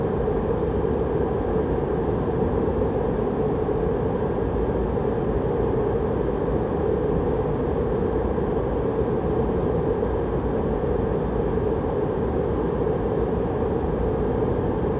H145_Wind_In-left.wav